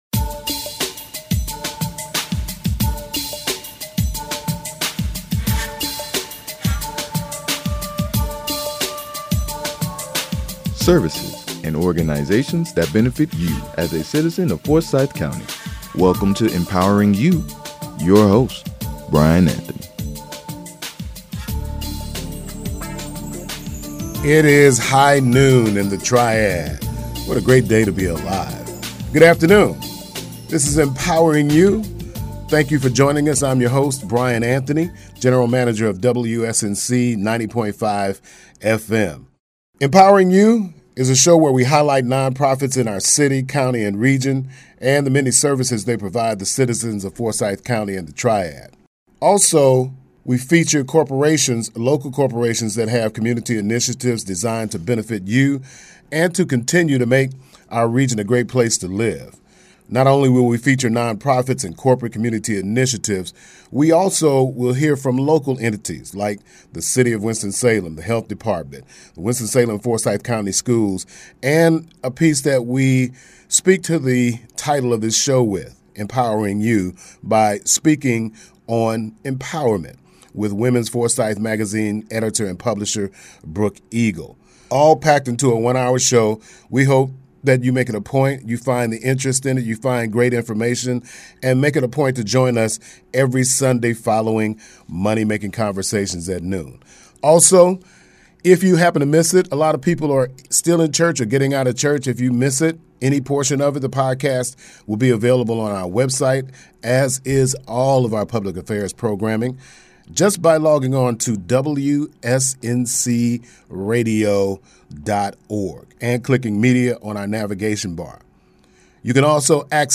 Empowering You is a 1-hour broadcast produced and recorded in the WSNC-FM Studios. The program is designed to highlight Non-Profit organizations, corporate community initiatives that are of benefit to the citizens of our community.